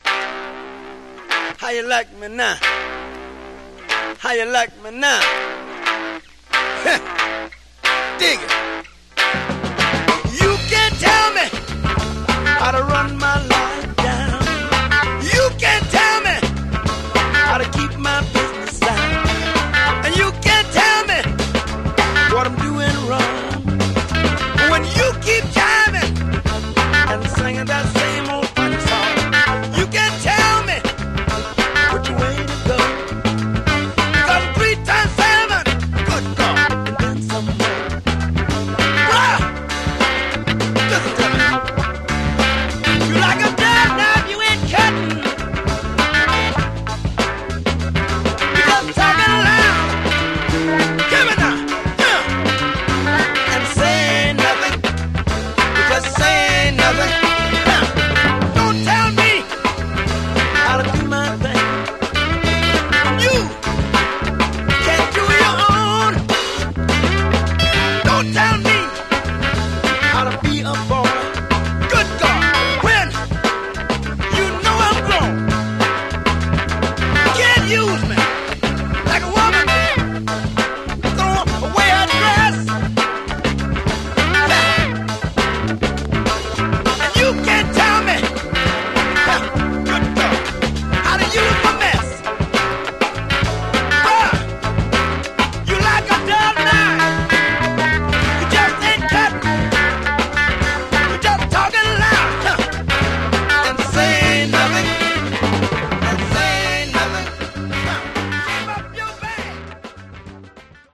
Genre: Funky Soul